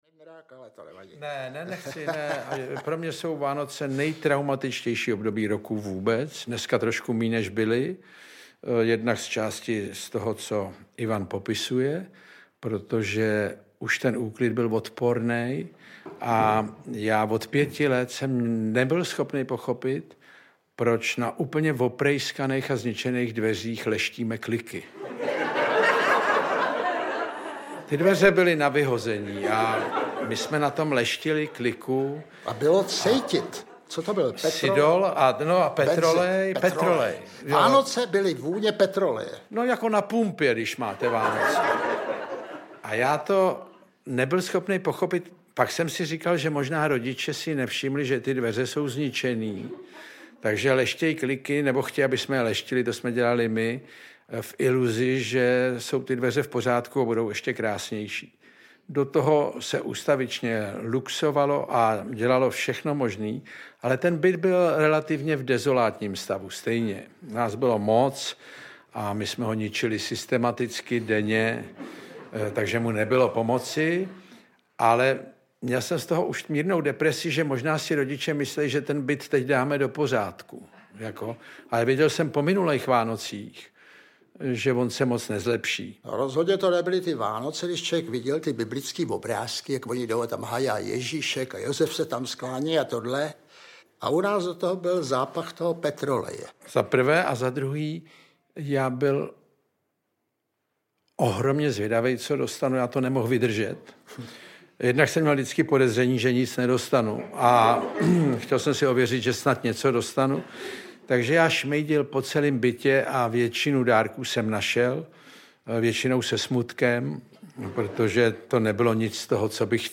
Vanilkové rohlíčky audiokniha
Záznam představení z pražské Violy.
• InterpretIvan Kraus, Jan Kraus